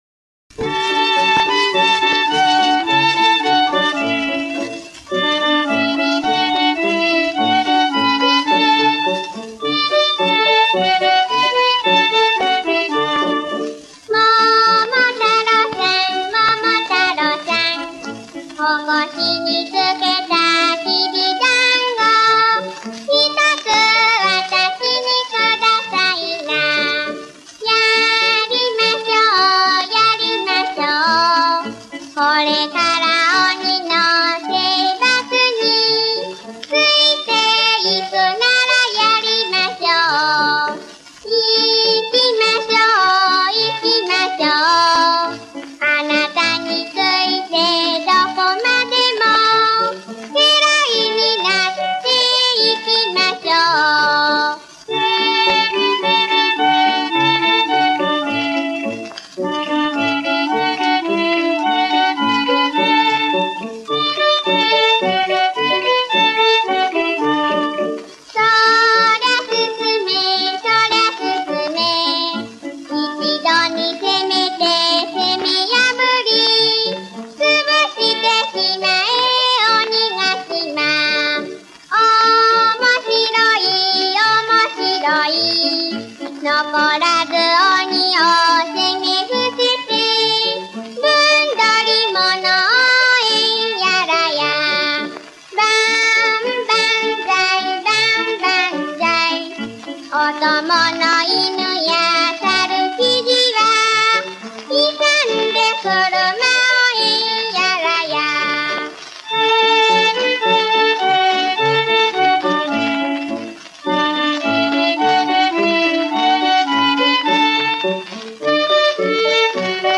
Momotaro” from Japanese nursery rhyme, 1911, composed by Teiichi Okano（1878 – 1941）.